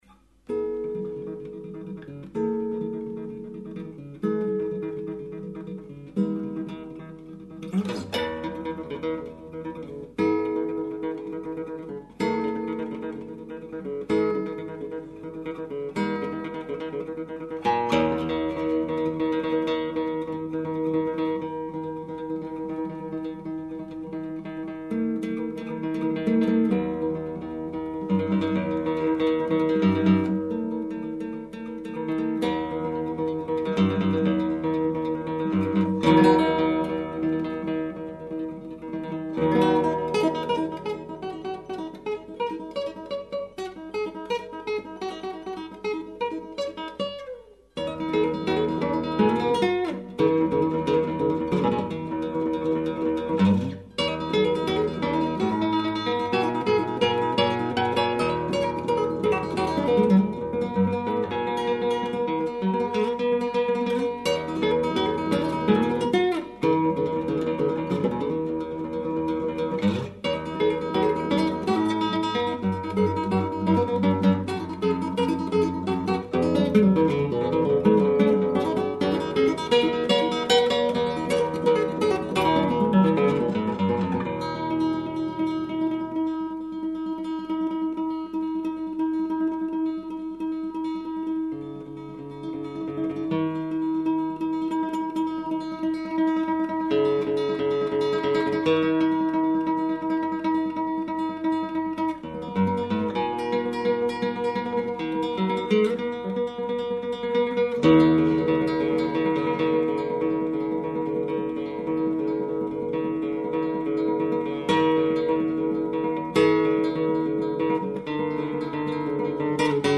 Chitarra sola